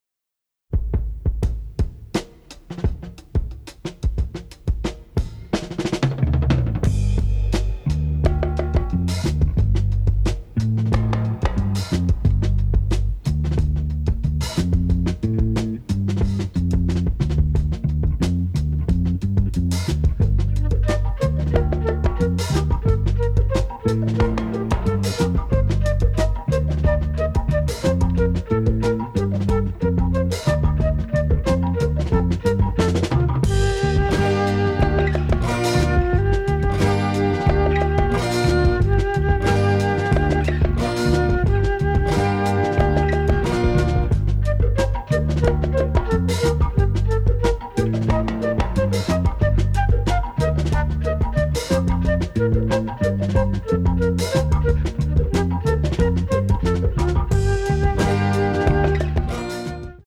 ethnic-flavored eclectic underscoring